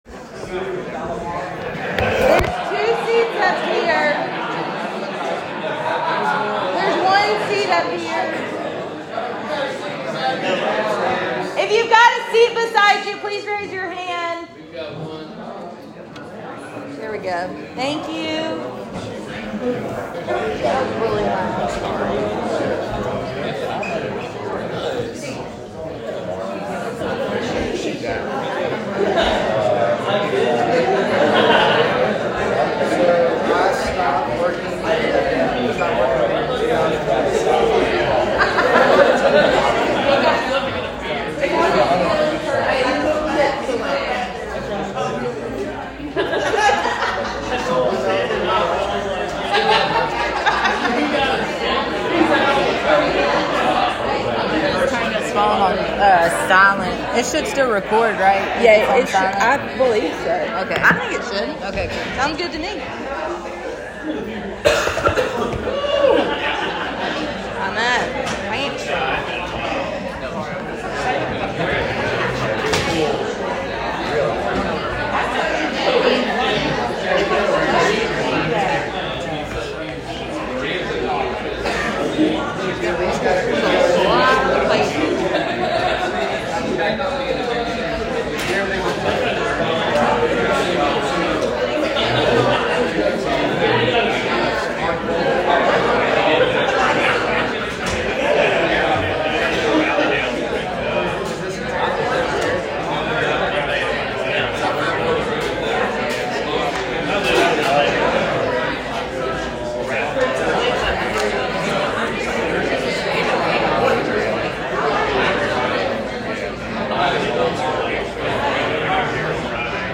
Speaking at The W.A.L.L.L Group CA 2yr Anniversary Birmingham, AL APR 2023